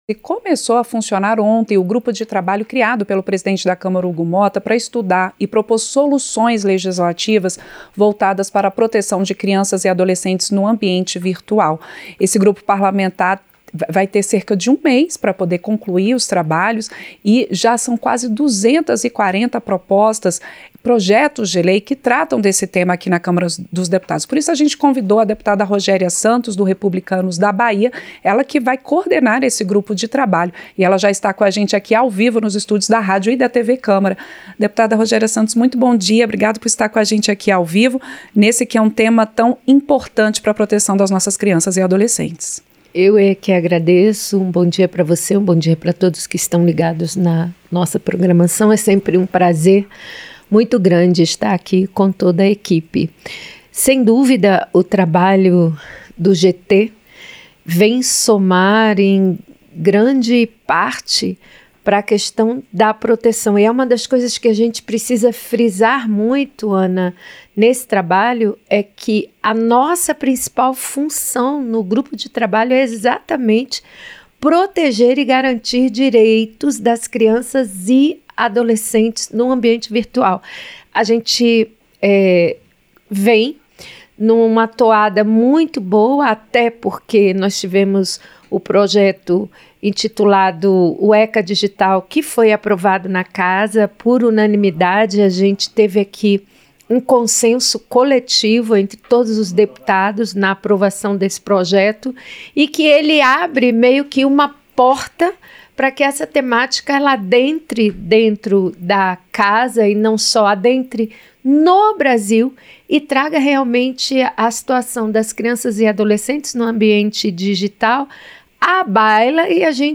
Entrevista - Dep. Rogéria Santos (Republicanos-BA)